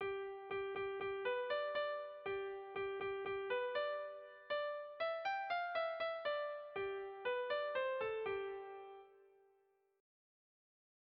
Erromantzea
Lauko txikia (hg) / Bi puntuko txikia (ip)
A-B